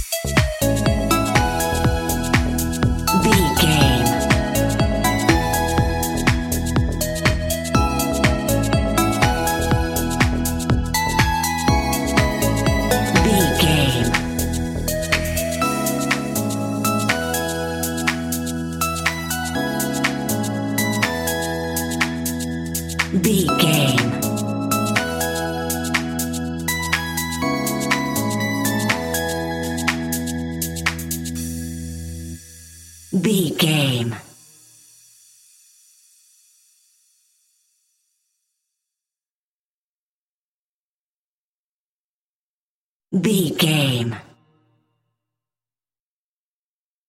Ionian/Major
groovy
uplifting
futuristic
driving
energetic
repetitive
joyful
synthesiser
electric piano
drum machine
house
electro house
synth pop
instrumentals
synth leads
synth bass